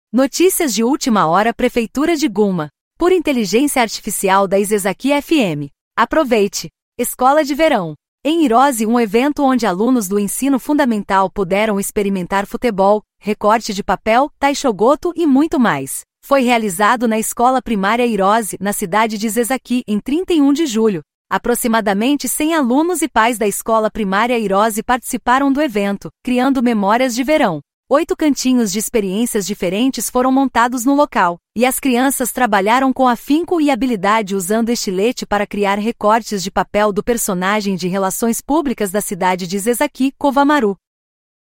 Notícias de última hora "Prefeitura de Gunma".Por AI da Isesaki FM."Aproveite!
Audio Channels: 1 (mono)